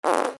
おなら
/ J｜フォーリー(布ずれ・動作) / J-25 ｜おなら・大便
『ブブゥ』